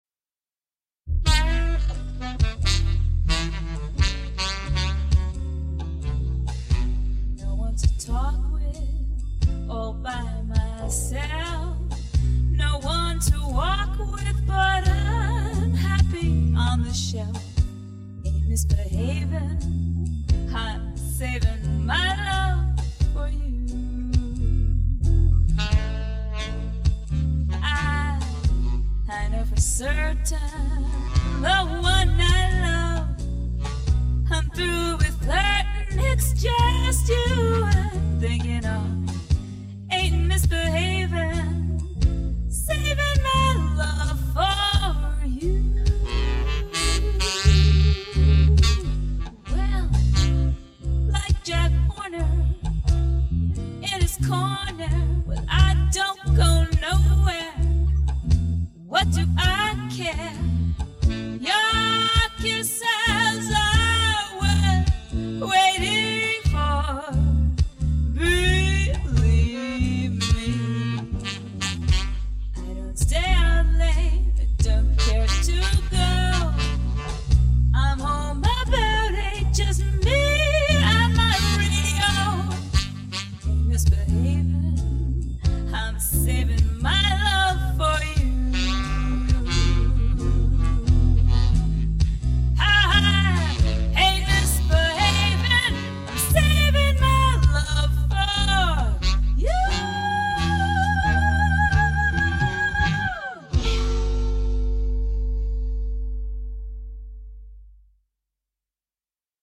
This CD was recorded at TallMan Studios in 1988.
saxaphone
bass
guitar
drums